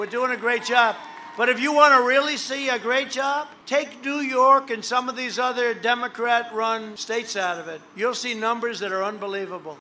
MOSINEE, WI (WBAP/KLIF) – At an rally in Wisconsin last night, President Donald Trump told supporters he had the COVID-19 pandemic under control.